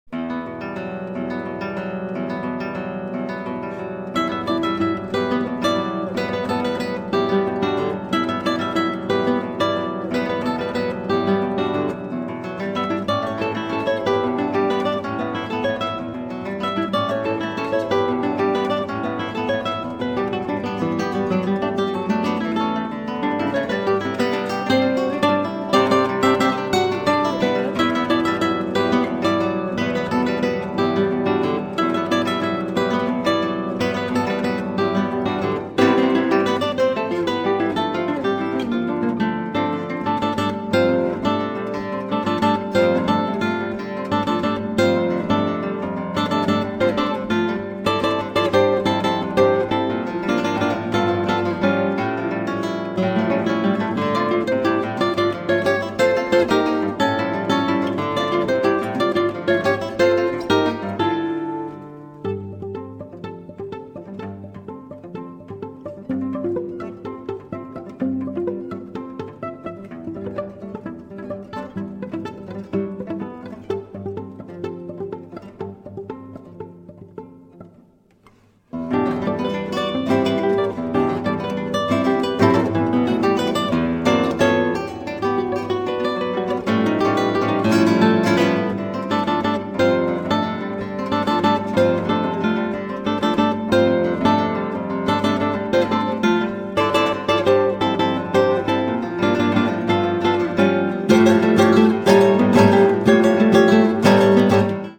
鮮烈かつ甘く響くギターが絶品です！
強力なエネルギーを放つかの如く発せられる瑞々しいギター・プレイが極上の味わいを産み出しています！